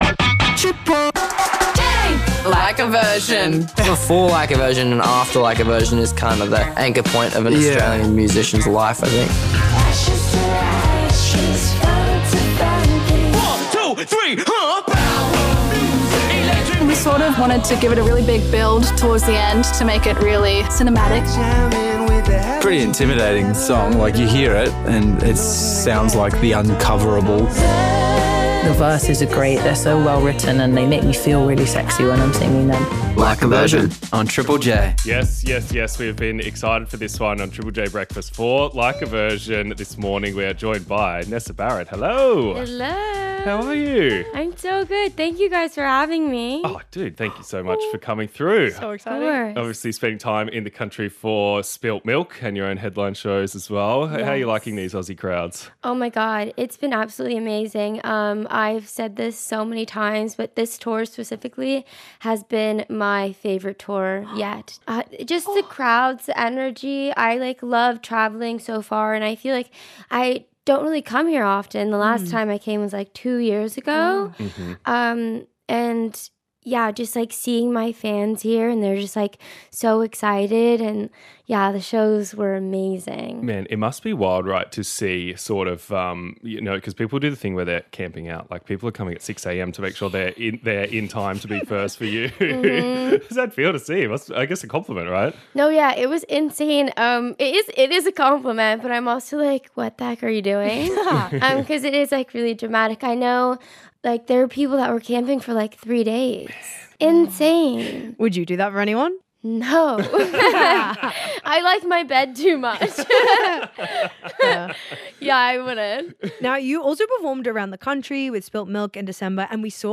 studio for the first time!